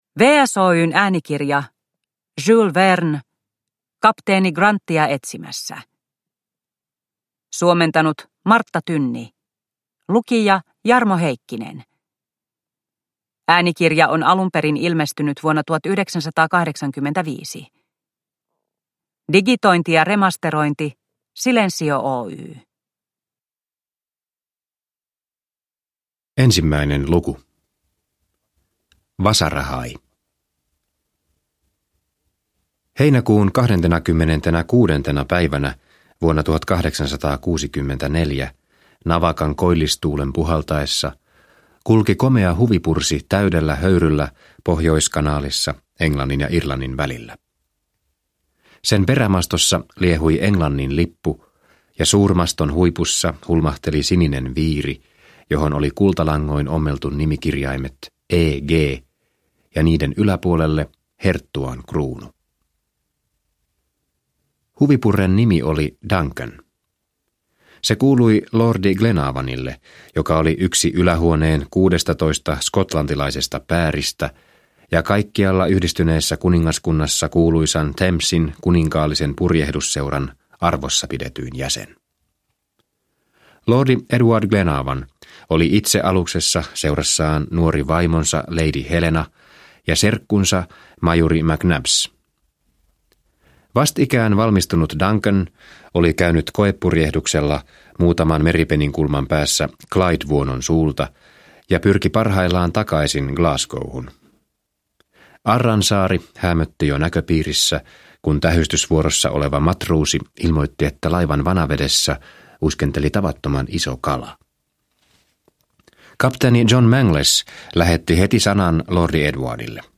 Kapteeni Grantia etsimässä – Ljudbok
Äänikirja on ilmestynyt alun perin vuonna 1985.